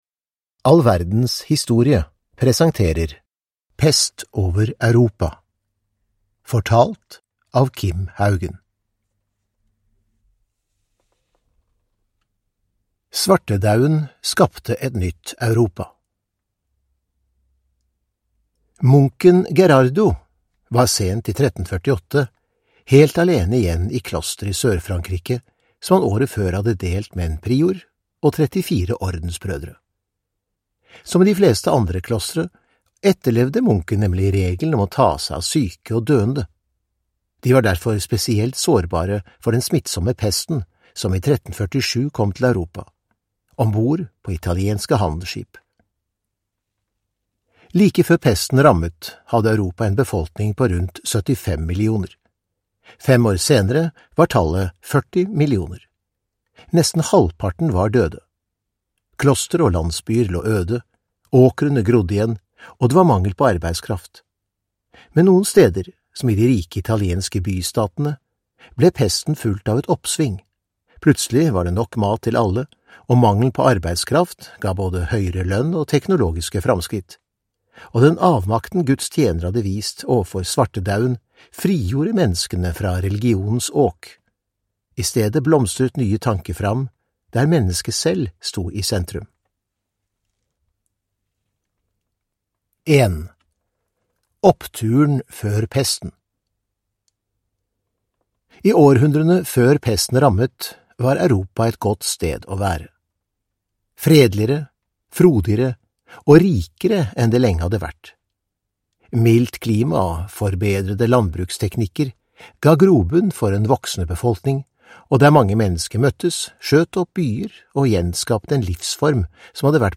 Pest over Europa (ljudbok) av All verdens historie